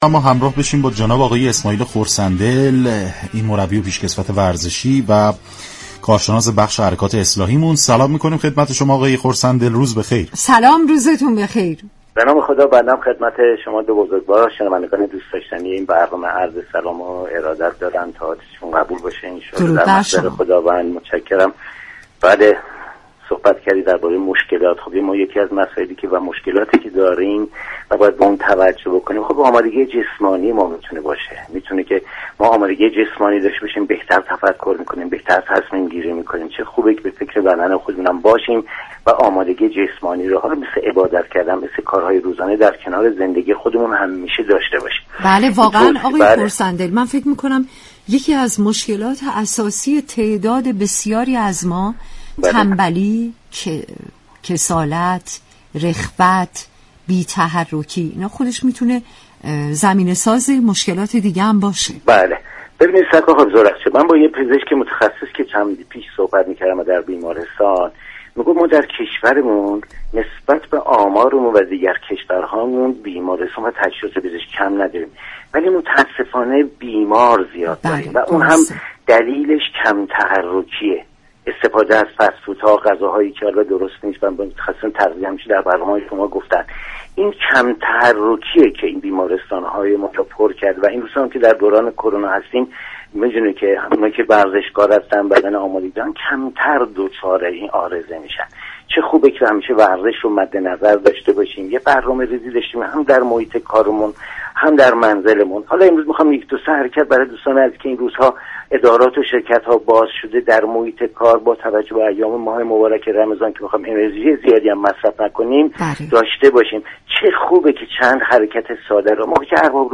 شما می توانید از طریق فایل صوتی پیوست شنونده بخشی از برنامه "گلخونه" رادیو ورزش كه به توضیح درباره ورزش مناسب برای كارمندان می پردازد؛ باشید.